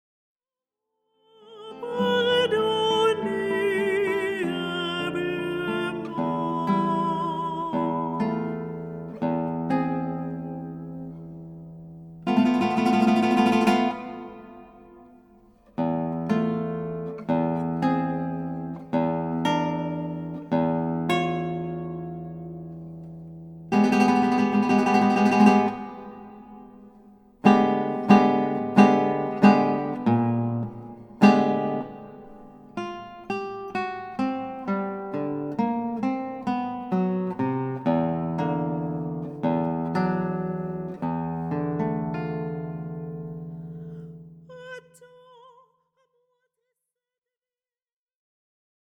Chants sacrés
La rencontre de la guitare et de la voix.
accompagnées à la guitare